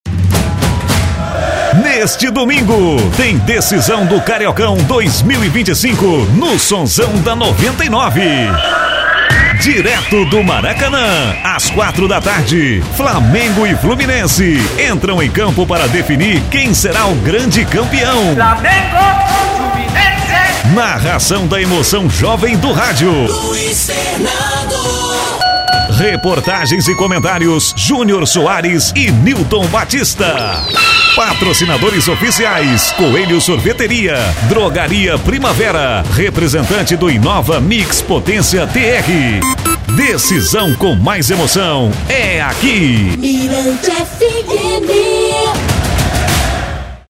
CHAMADA FUTEBOL FINAL PARAZÃO2025
chamada_flaxflu_final.mp3